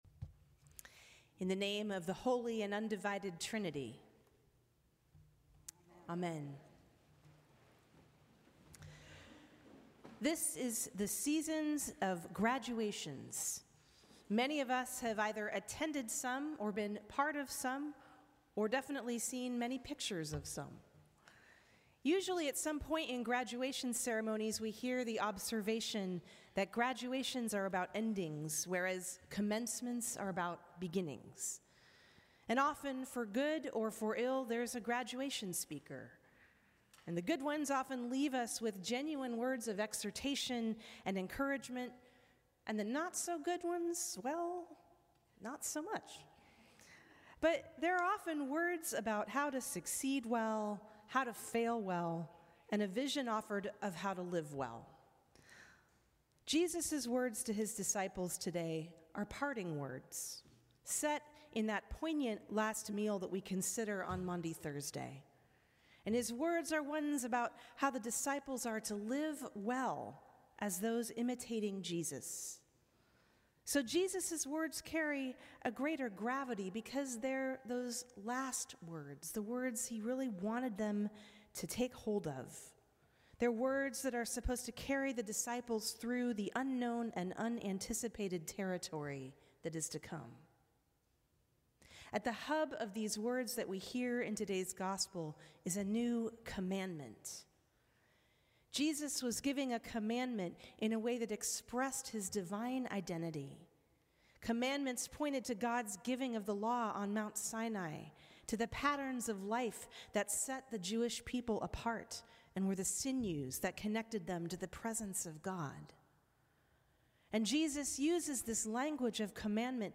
Sermons from St. Cross Episcopal Church Fifth Sunday of Easter May 22 2025 | 00:10:56 1x 00:00 / 00:10:56 Subscribe Share Apple Podcasts Spotify Overcast RSS Feed Share Link Embed